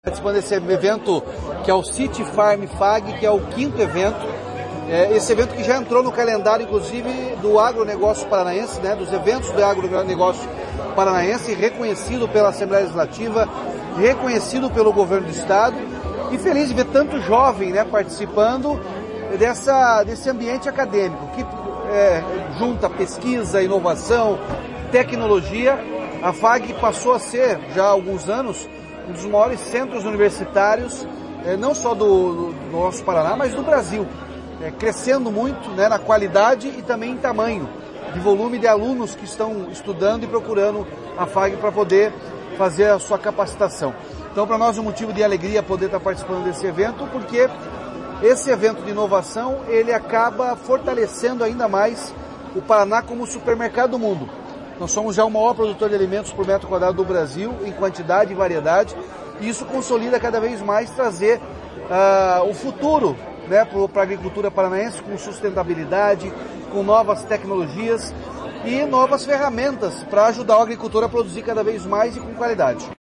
Sonora do governador Ratinho Júnior sobre evento do agro paranaense